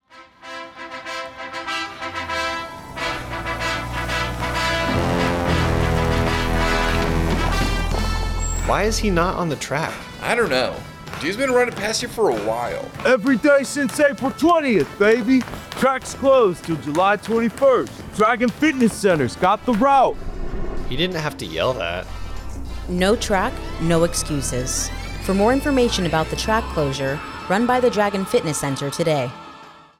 A radio spot highlighting the Dragon Fitness Center’s track closure at Aviano Air Base, Italy. The Dragon Fitness Center has provided an alternate route for runners during the closure, sustaining the 31st Fighter Wing’s ability to deploy and fight from home.